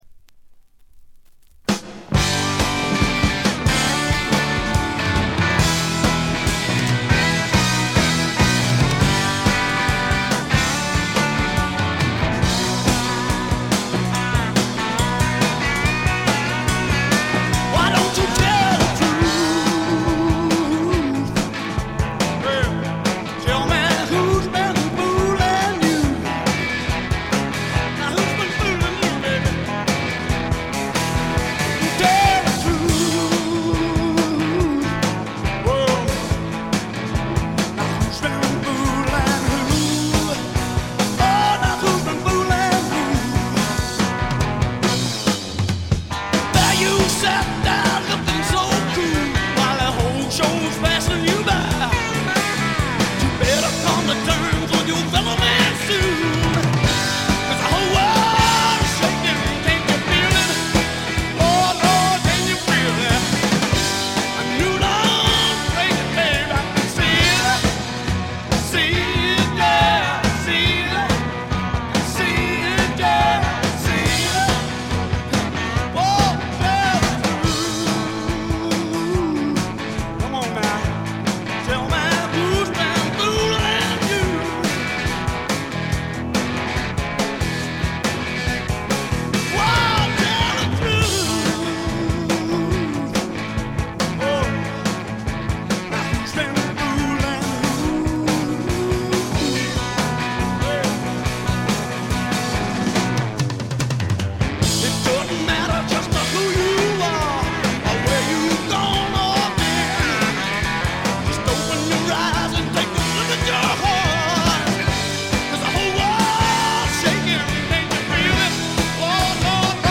濃厚なスワンプ味に脳天直撃される傑作です。
試聴曲は現品からの取り込み音源です。